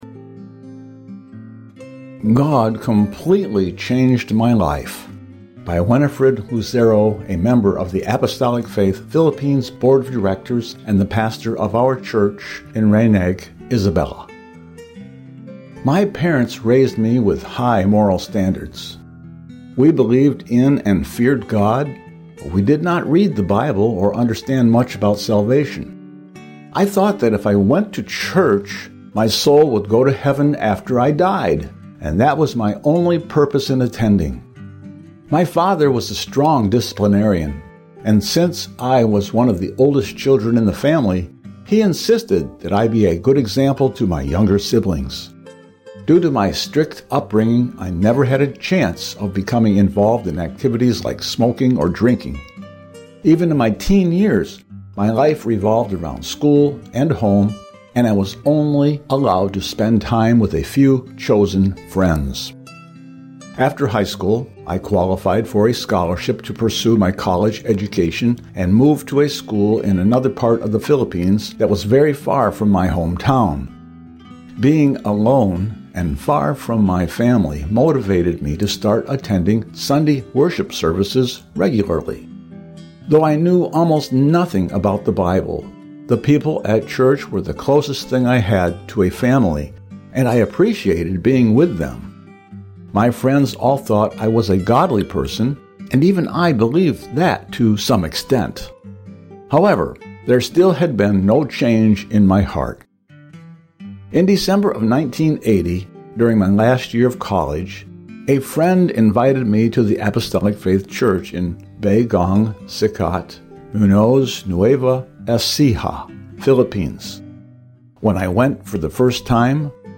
Witness
A Filipino pastor tells how God guided him to the truth.